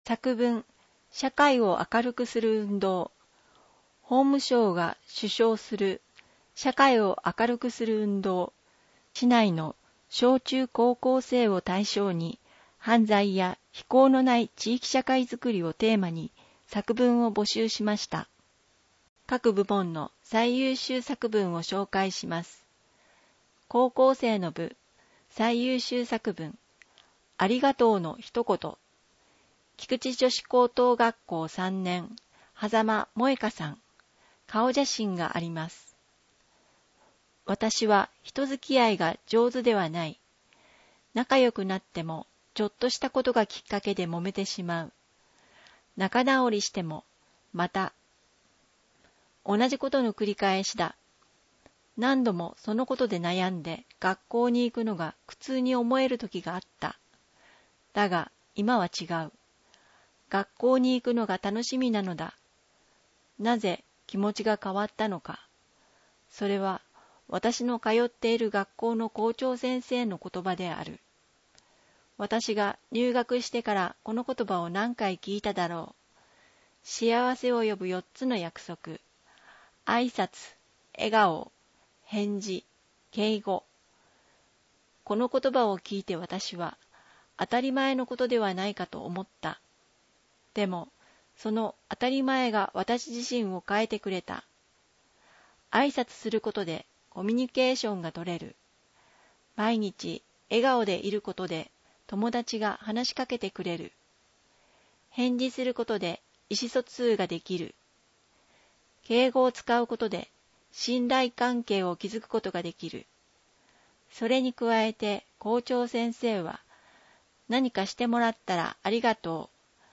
音訳